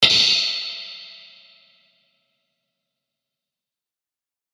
/ F｜演出・アニメ・心理 / F-10 ｜ワンポイント マイナスイメージ_
感情：怒る 02
ピキーン